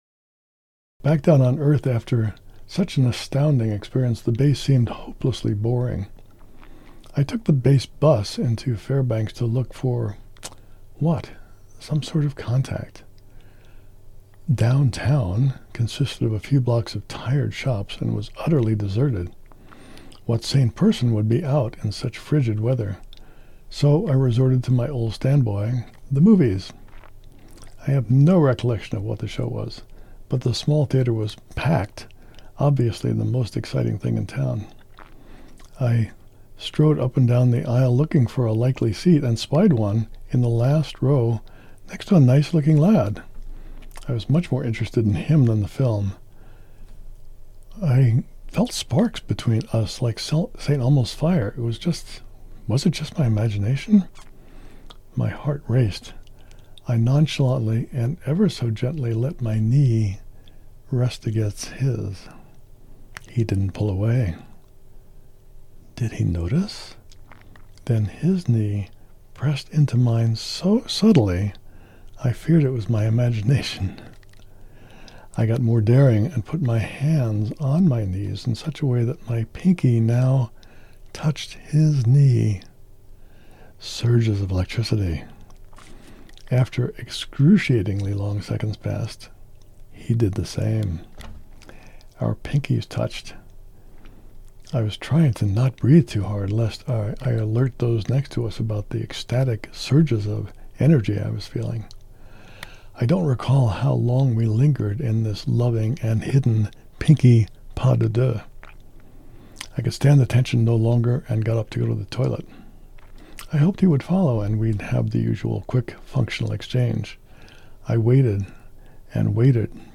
• Audiobook • 9 hrs, 20 mins